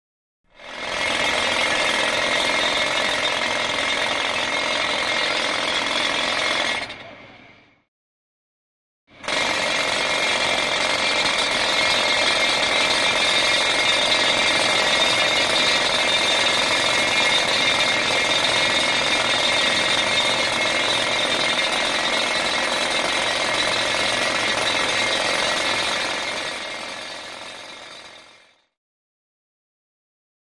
Large Pneumatic Drill; Drilling; Loud Continuous Metallic Clanking, With Motor Noise, Two Times Medium Perspective.